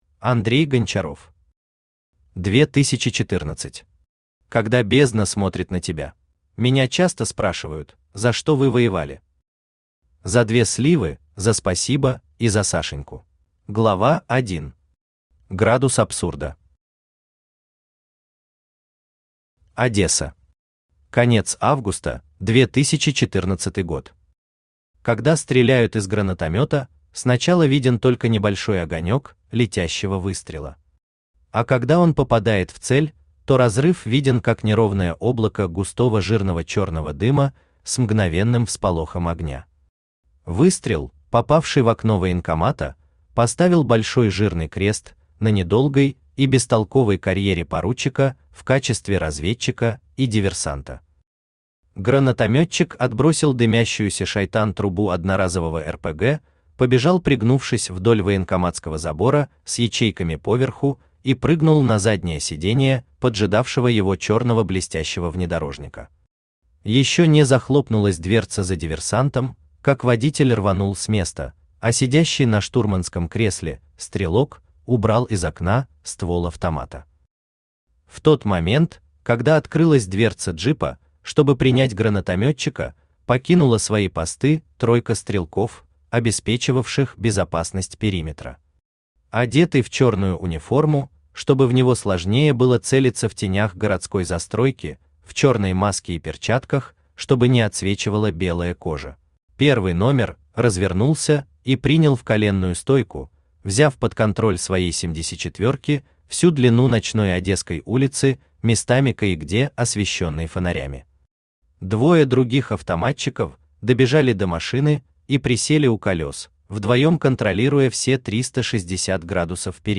Когда бездна смотрит на тебя Автор Андрей Гончаров Читает аудиокнигу Авточтец ЛитРес.